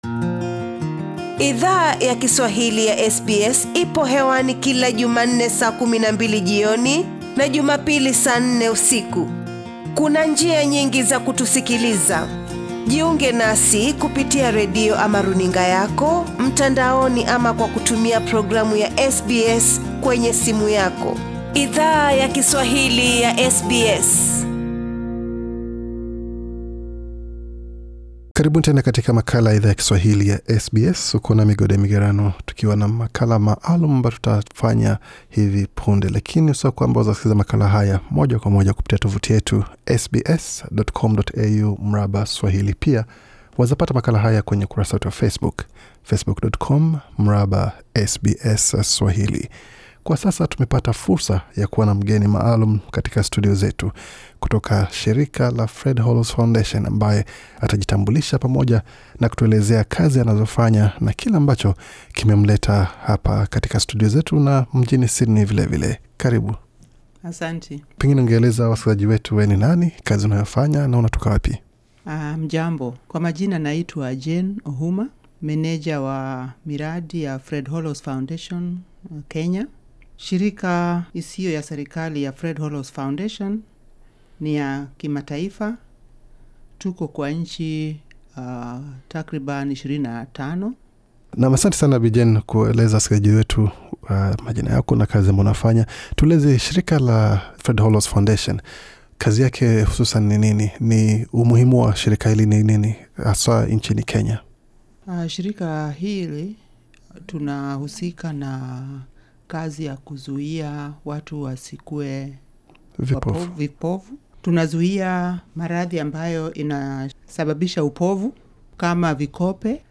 akiwa katika studio ya SBS